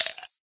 plasticice.ogg